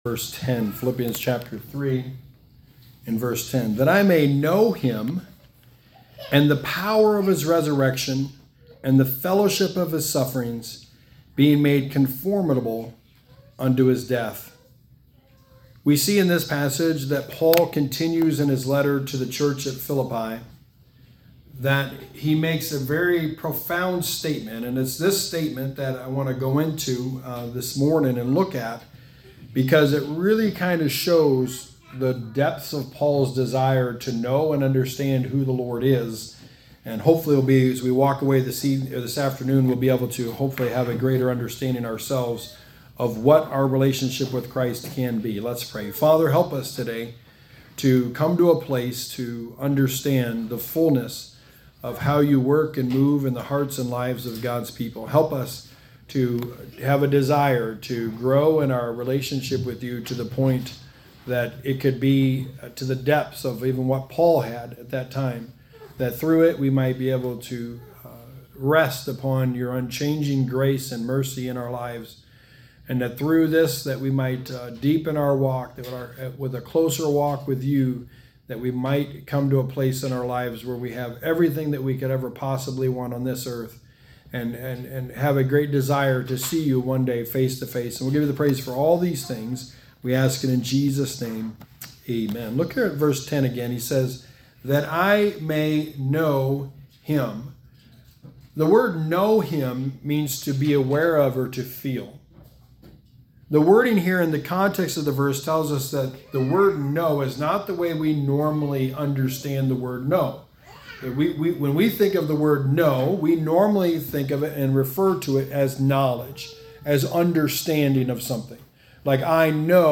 Sermon 19: The Book of Philippians: A Closer Walk With Christ